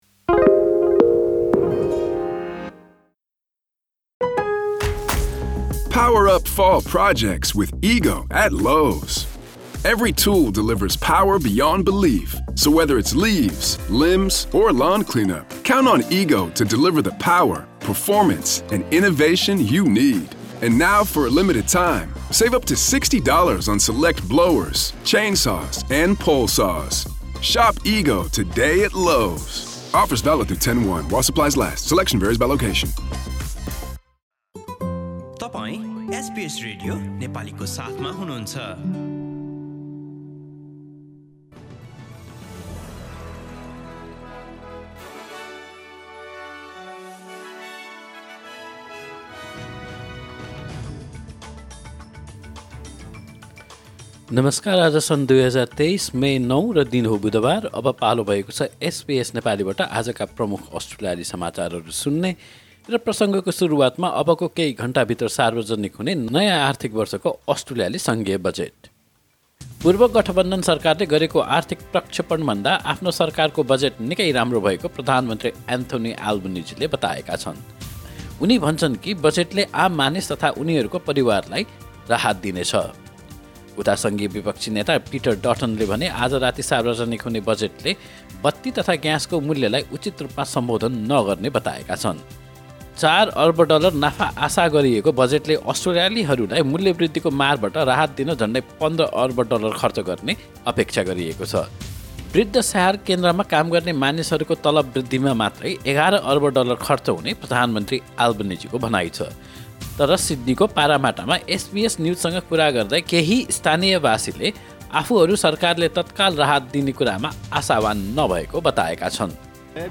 एसबीएस नेपाली प्रमुख अस्ट्रेलियाली समाचार : मङ्गलवार, ९ मे २०२३